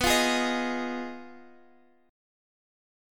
B6b5 chord